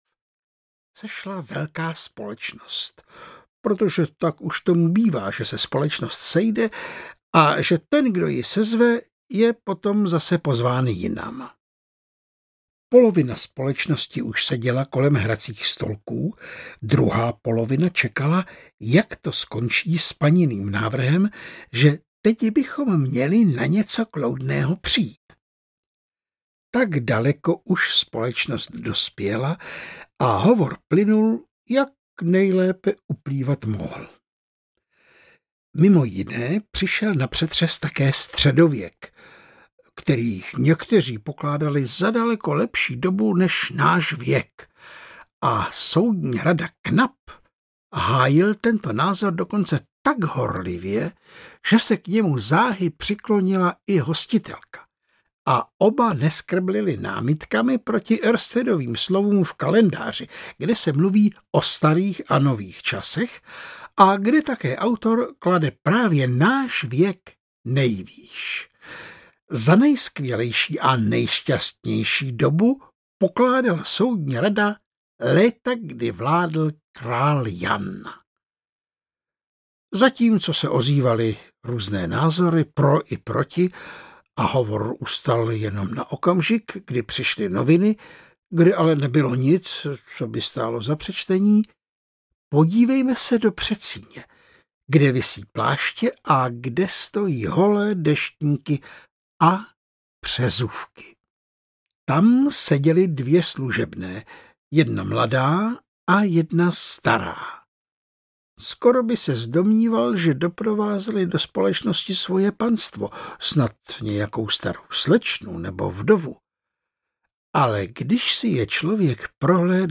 Přezůvky Štěstěny audiokniha
Ukázka z knihy